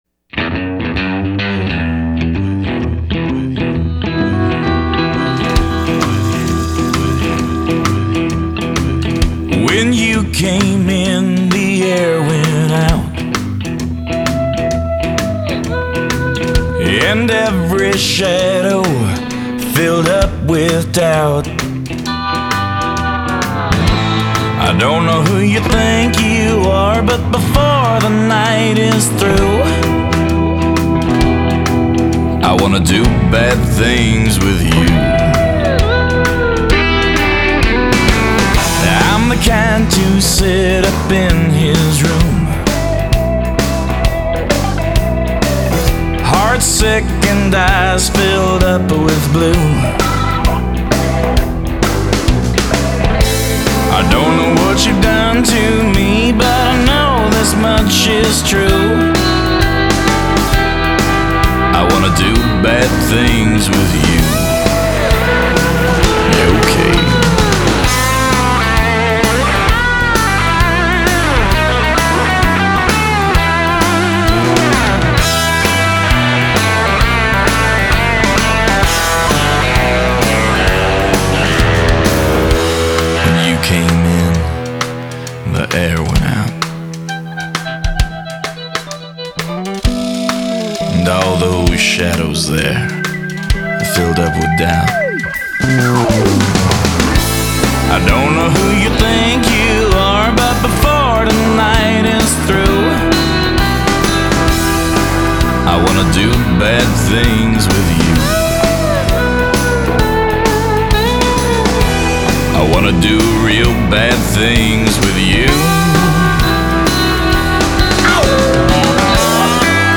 Release Year: 2005 Genres: Southern Rock, Swamp Rock ...